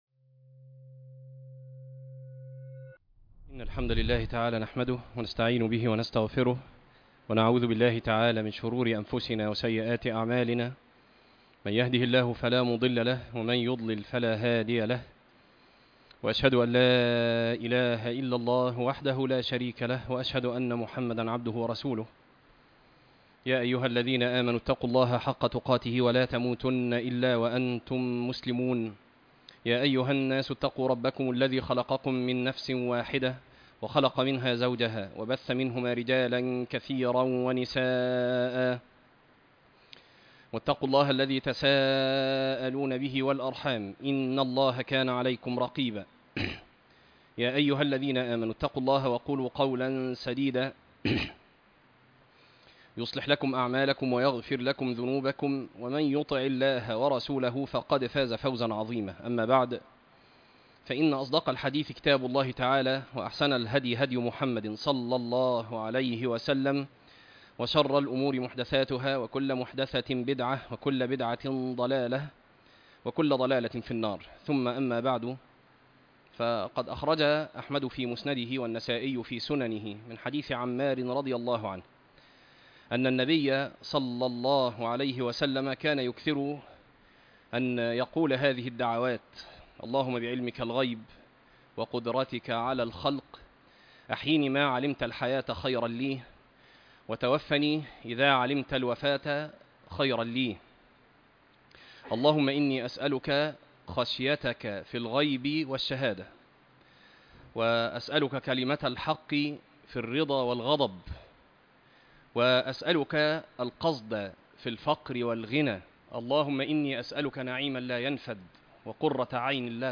تفاصيل المادة عنوان المادة ١- اللهم بعلمك الغيب - خطبة تاريخ التحميل الجمعة 10 ابريل 2026 مـ حجم المادة غير معروف عدد الزيارات 1 زيارة عدد مرات الحفظ 0 مرة إستماع المادة حفظ المادة اضف تعليقك أرسل لصديق